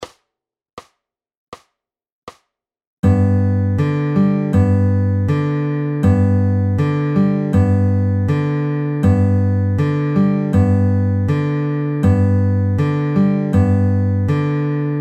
Both pinches now are at the same time as our G bass note (the 3rd fret of the low E or thickest string).
Adding a second pinch